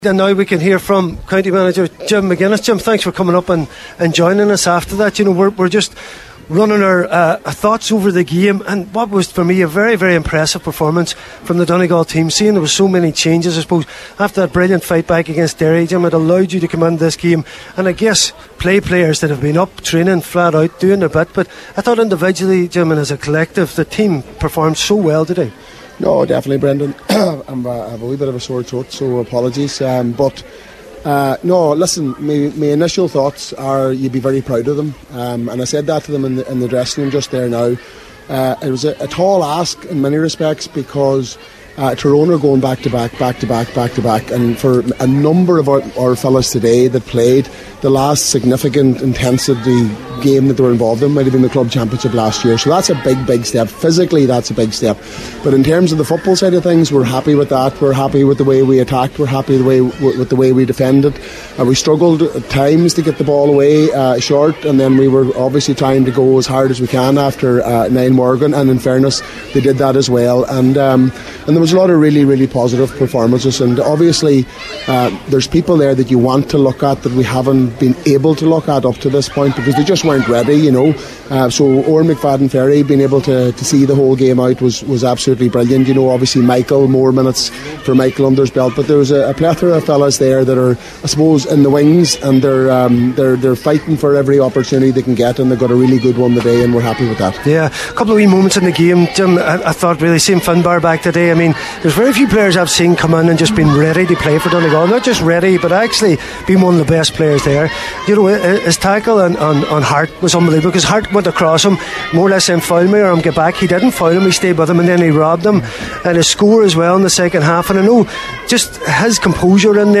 live at the O’Donnell Park after an understrength Donegal side were defeated 0-25 to 0-19 by Tyrone this afternoon.